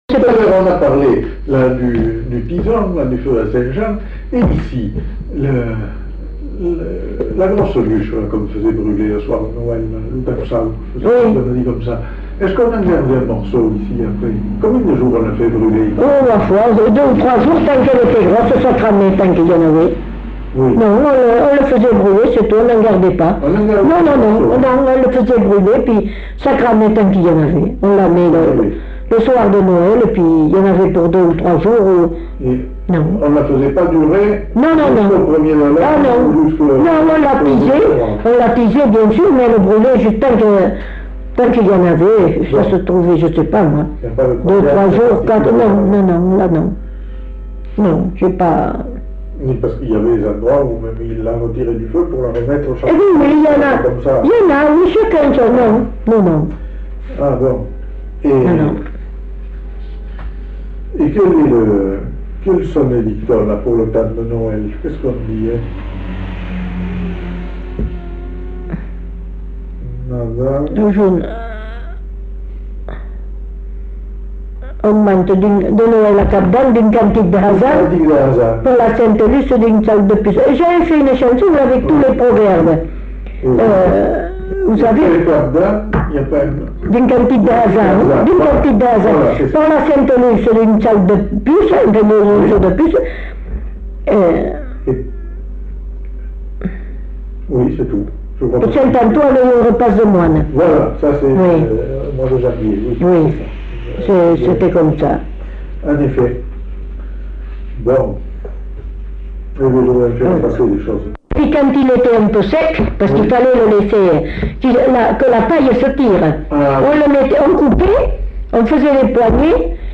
Lieu : Belin-Beliet
Genre : témoignage thématique